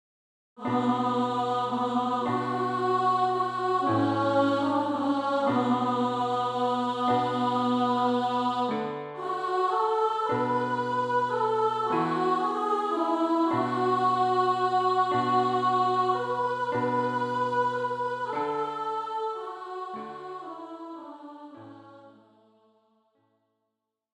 für Gesang, mittlere Stimme